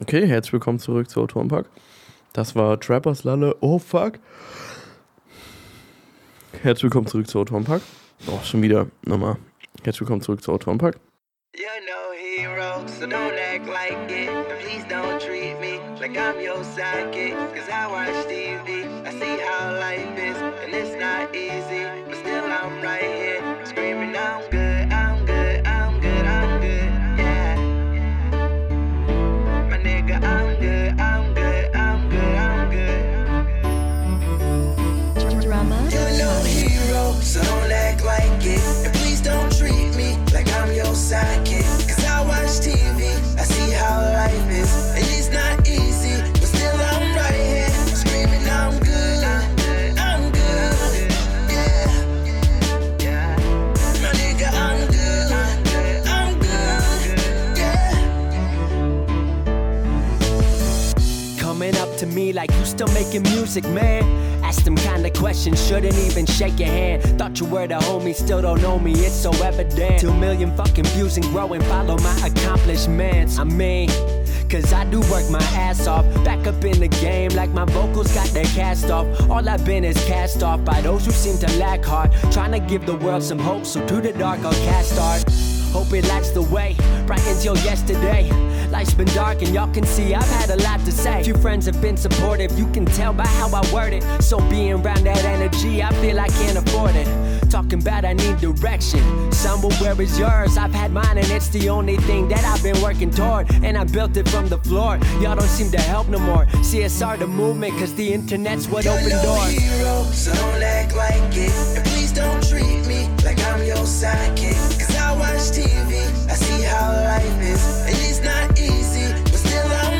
Wir reden über das Schreiben, Genres, Geschichten oder erzählen Geschichten und spielen zwischendurch Musik, die uns gefällt. Heute senden wir die Wiederholung von letztem Sonntag, in der wir über das Genre History reden.